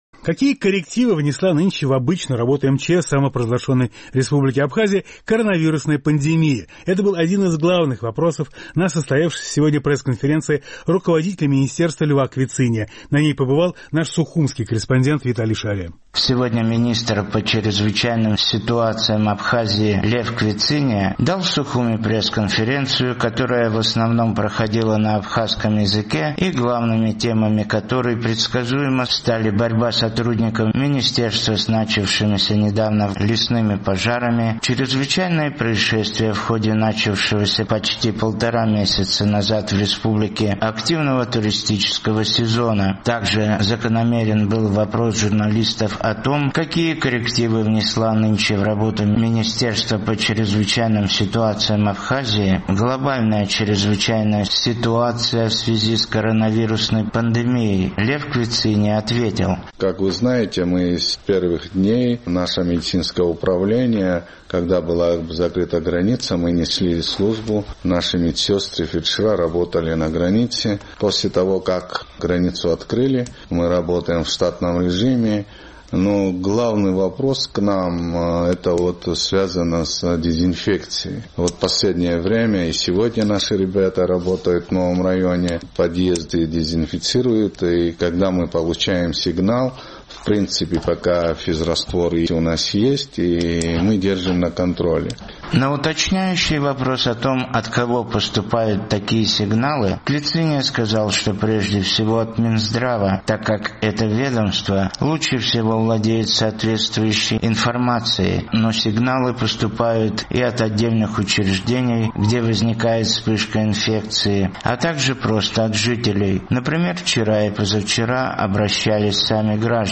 Сегодня министр по чрезвычайным ситуациям Абхазии Лев Квициния дал в Сухуме пресс-конференцию, которая в основном проходила на абхазском языке и главными темами которой предсказуемо стали борьба сотрудников министерства с начавшимися недавно в горной местности лесными пожарами и возгораниями...